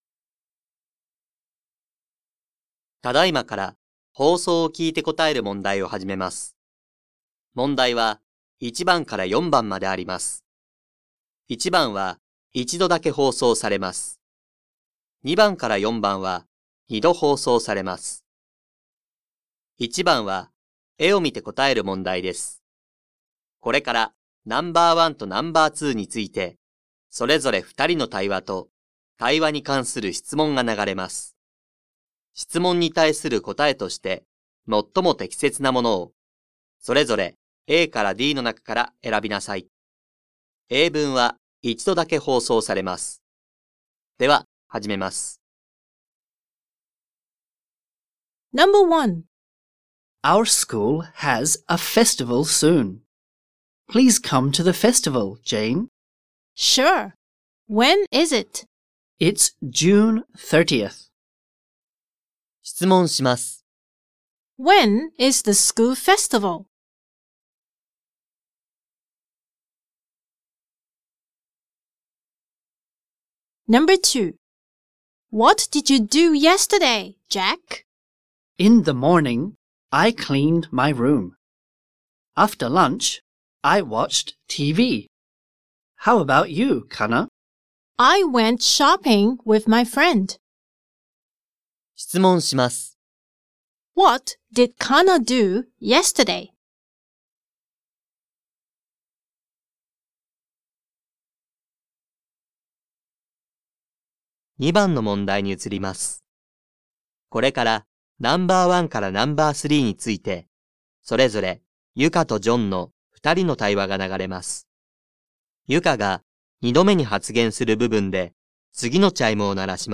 2024年度２年３号英語のリスニングテストの音声